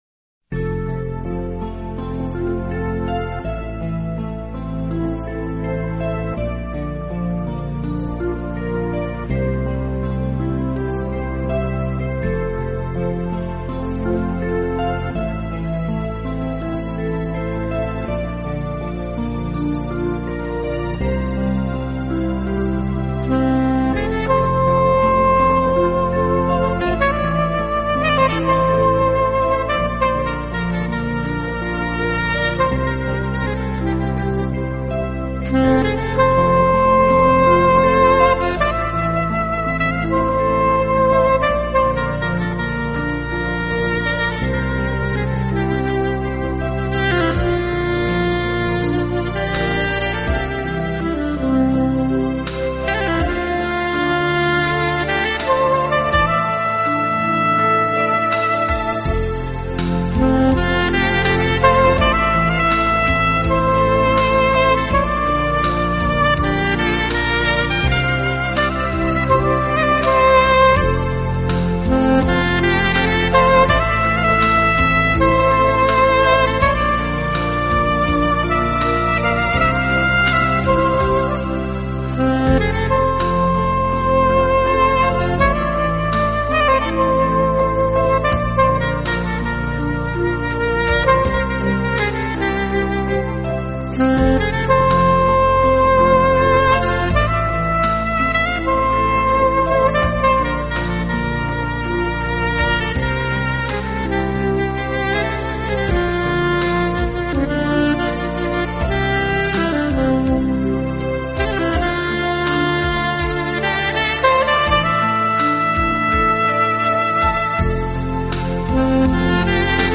Genre: Funk
Channels: 48Khz, stereo